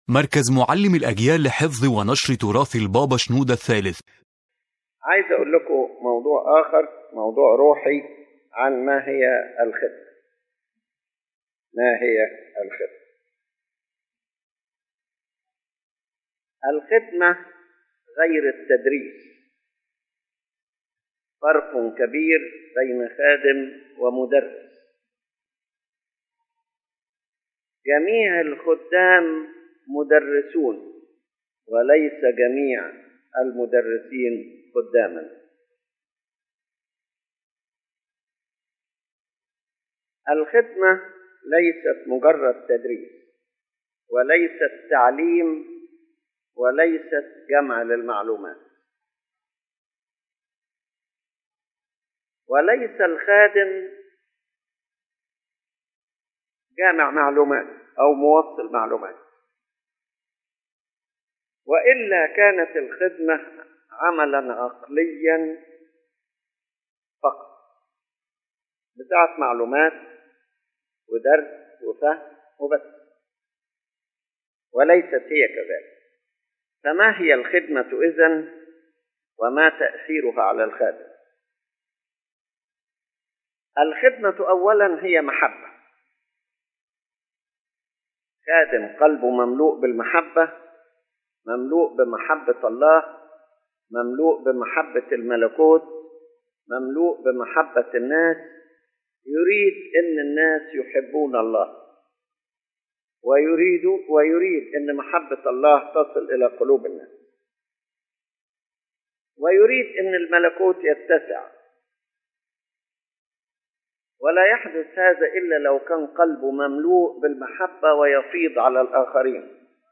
The General Idea of the Lecture